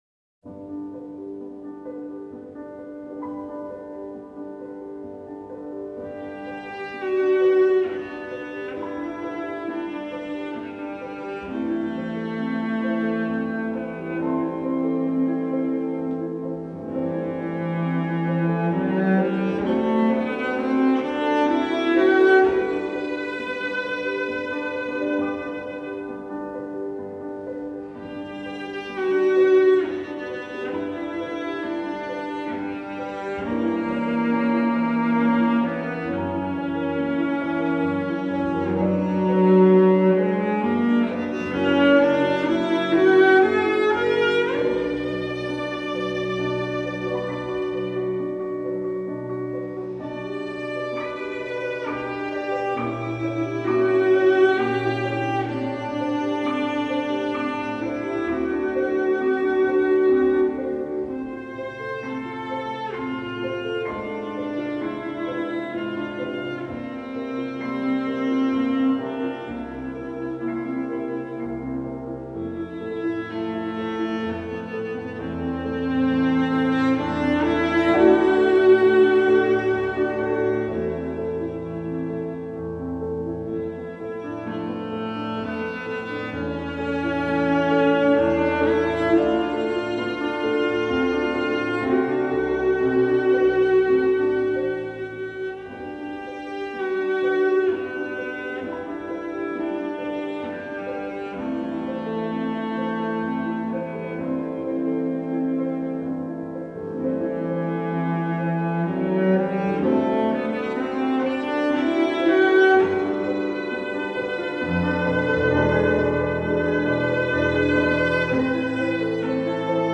大提琴（Cello）